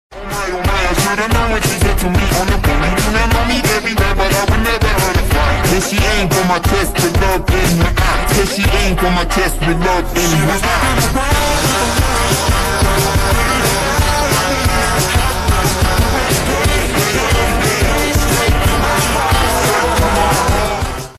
sorry for really bad audio, sound effects free download